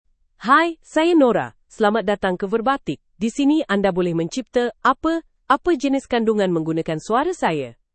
Nora — Female Malayalam (India) AI Voice | TTS, Voice Cloning & Video | Verbatik AI
Nora is a female AI voice for Malayalam (India).
Voice sample
Listen to Nora's female Malayalam voice.
Female